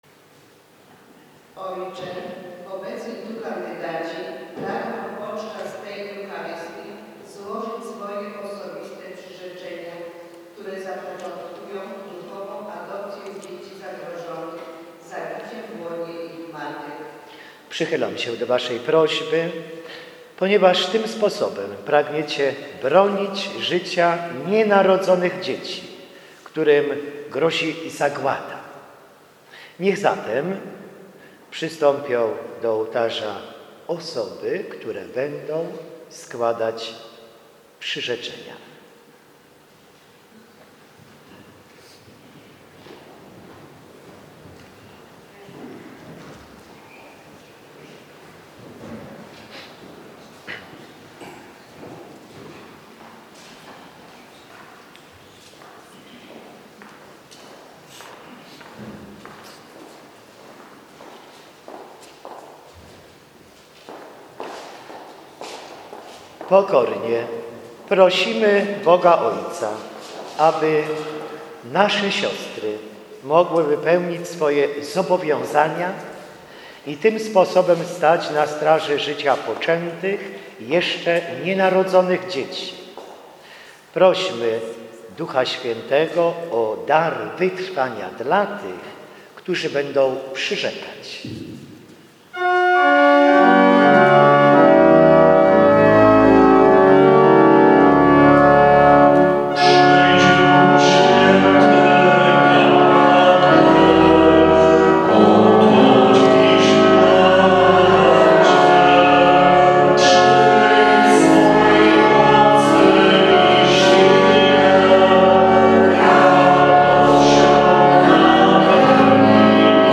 Osoby , które przystąpiły do  tego dzieła złożyły uroczyste przyrzeczenie Duchowej Adopcji Dziecka Poczętego w Uroczystość Zwiastowania Najświętszej Maryi Panny  dnia 25 marca 2015r o godz. 9,00 w kościele św. Jadwigi   w Walimiu podczas rekolekcji.
przyrzeczenie.mp3